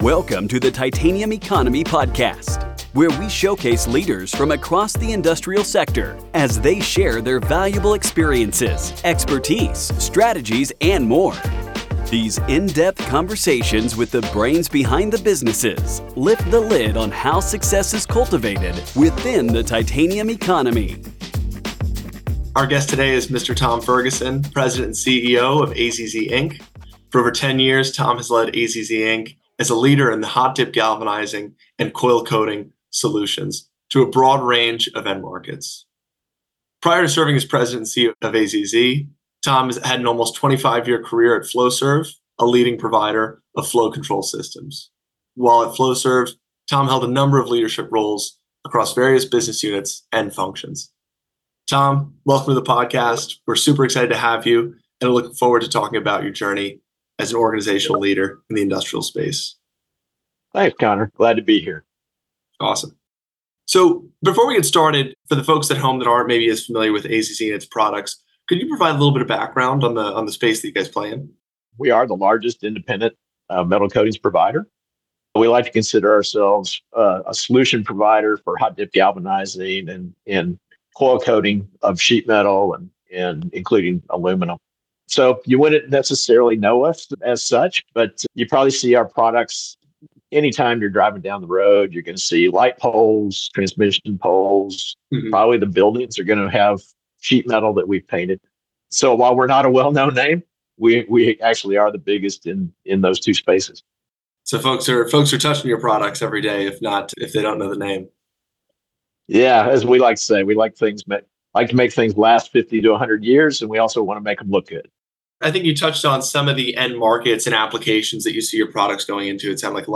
The Titanium Economy Podcast